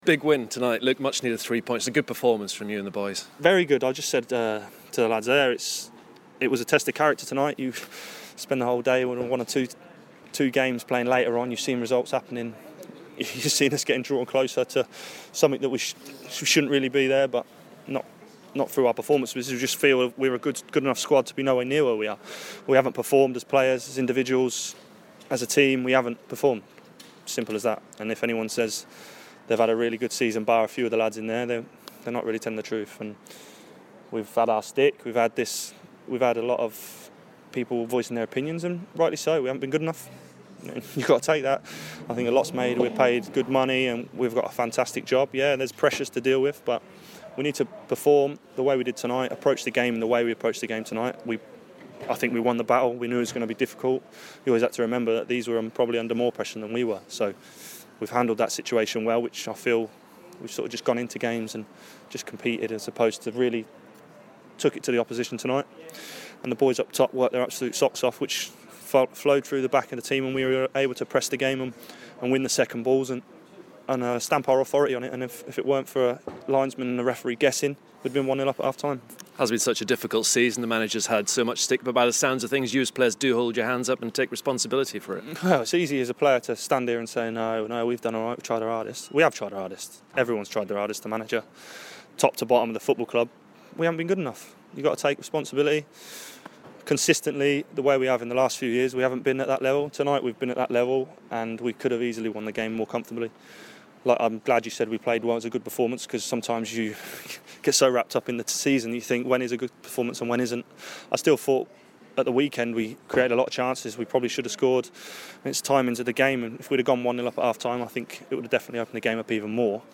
Ipswich Town captain Luke Chambers with BBC Suffolk after the Blues' 2-1 win at Burton Albion.